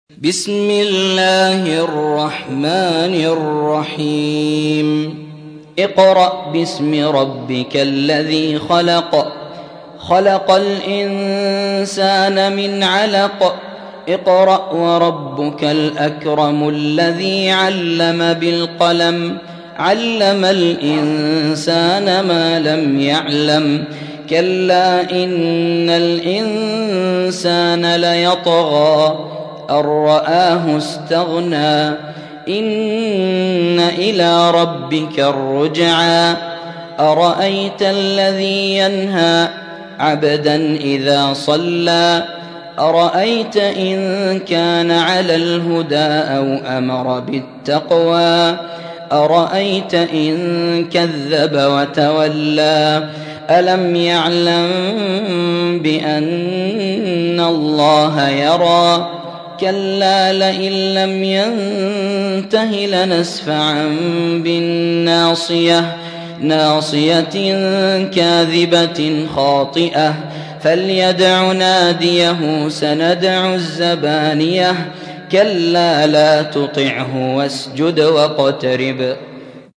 96. سورة العلق / القارئ